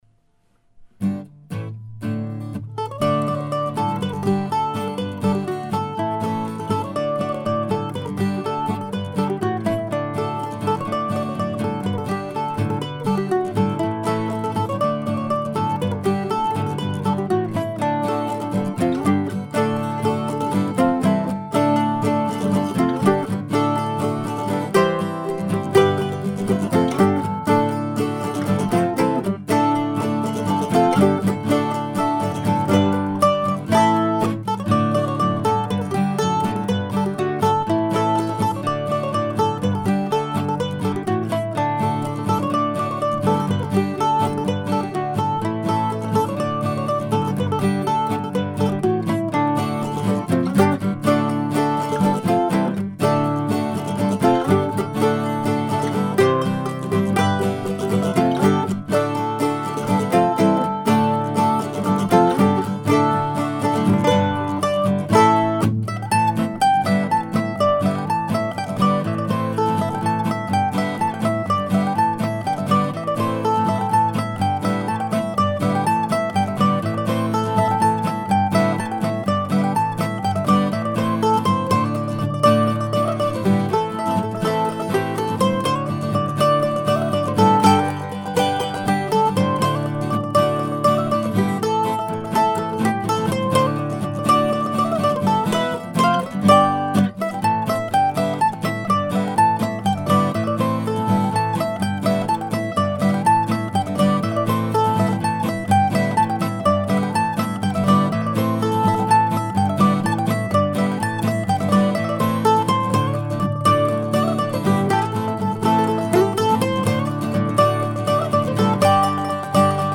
Maybe these are old-time tunes, maybe not.